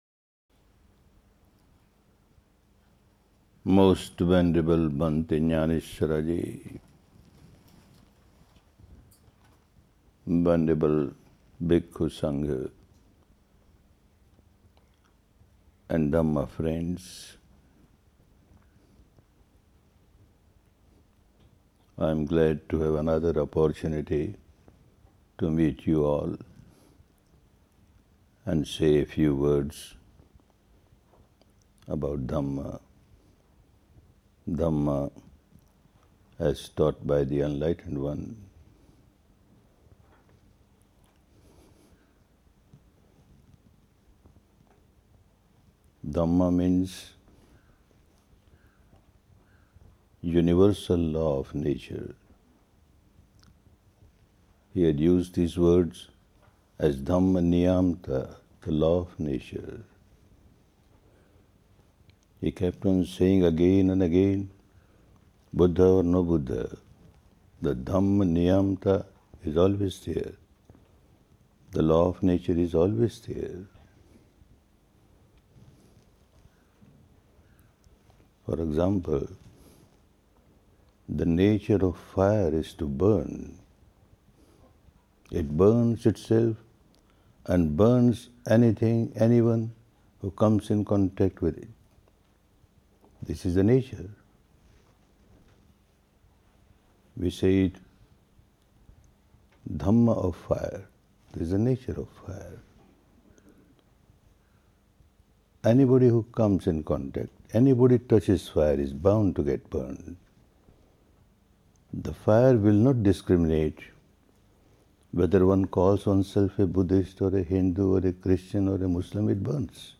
Sitagu University, Sagaing Hills, Mandalay, Myanmar • 3-Day Talk + Q&A - Day 01